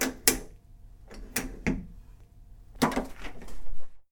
ドア開内から二重ロック
op_hotel_door1.mp3